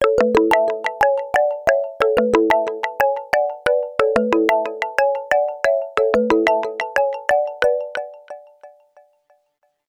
эхо , ксилофон